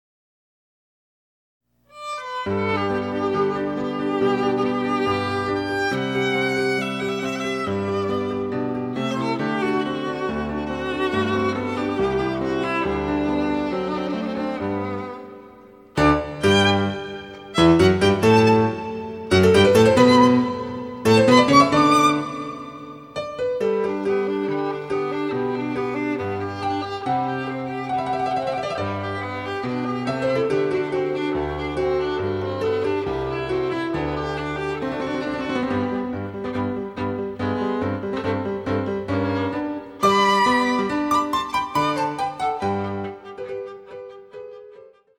ヴァイオリン演奏
(1)各楽章につきモダンピッチ(A=442Hz)の伴奏